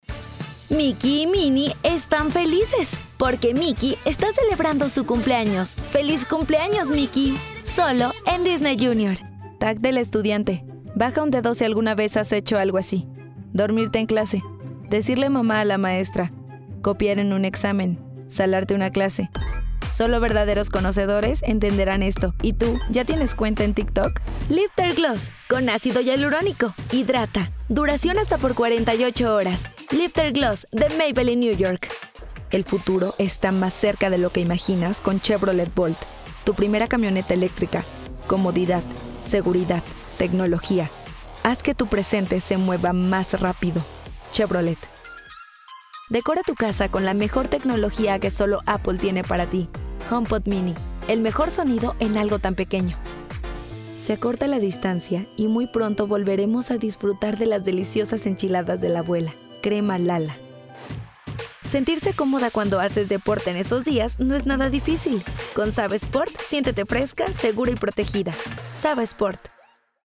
Locutora